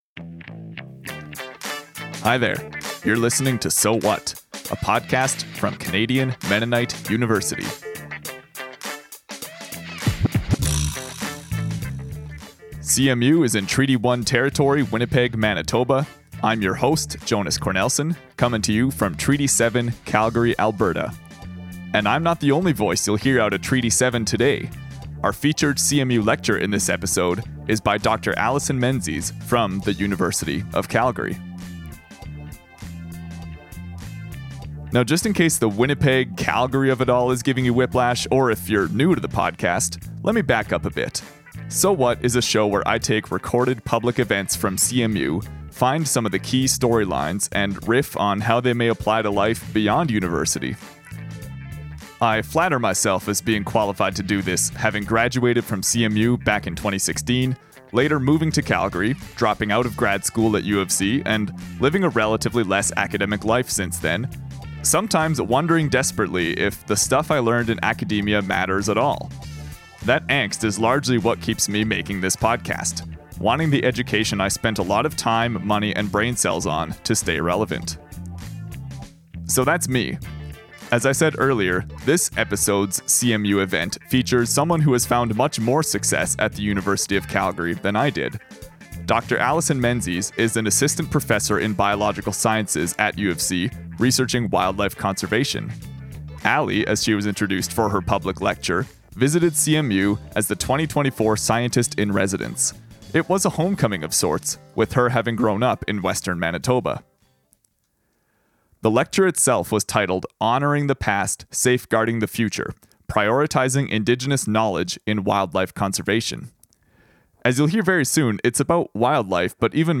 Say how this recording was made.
Listen to "Moose Matters": Download Episode So What? is a quarterly CMU podcast that draws out key ideas from public events at Canadian Mennonite University.